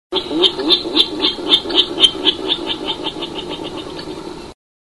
Virginia Rail Grunt Call, click here.
13_VIRA_grunt.mp3